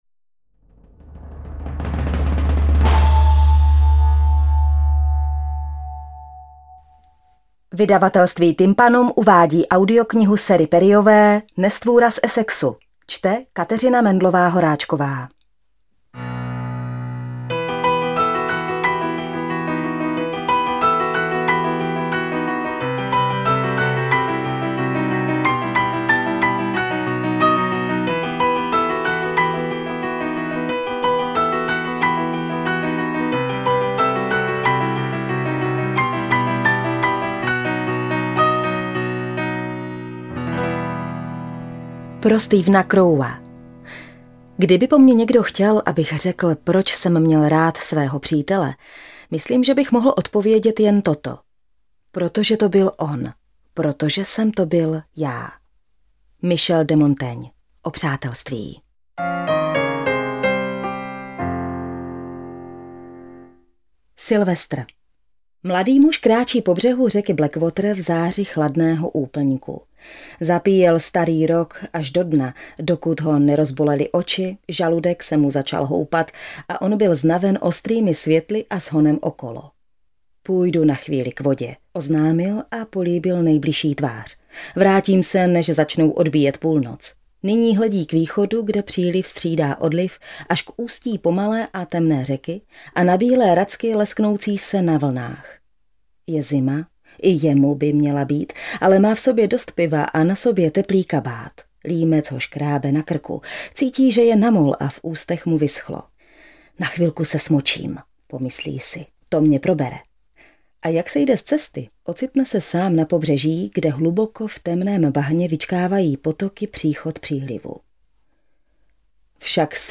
AudioKniha ke stažení, 77 x mp3, délka 13 hod. 1 min., velikost 717,3 MB, česky